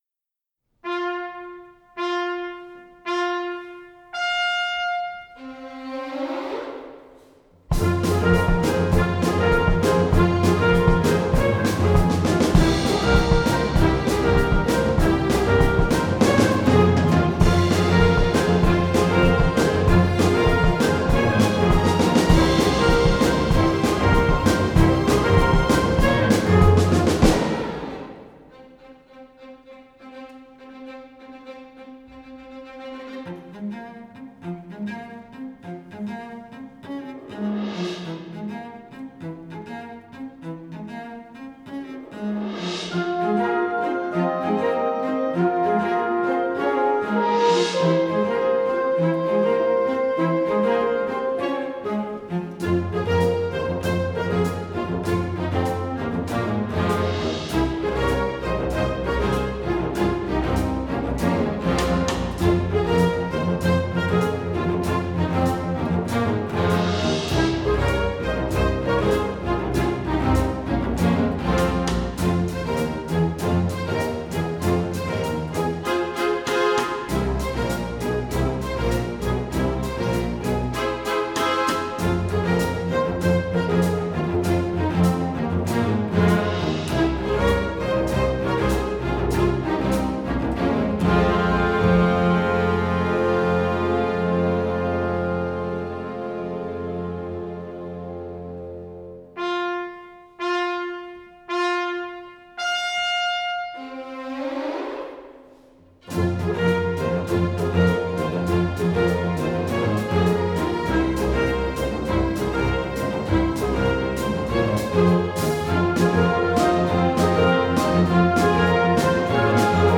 Varje år samarbetar Svenska Kammarorkestern med Karolinska Gymnasiet runt föreställningen SkRiKa.
Svenska Kammarorkestern